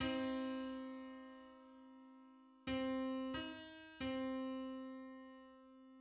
File:Hundred-fifty-fifth harmonic on C.mid - Wikimedia Commons
Just: 155:128 = 331.35 cents.
Public domain Public domain false false This media depicts a musical interval outside of a specific musical context.
Hundred-fifty-fifth_harmonic_on_C.mid.mp3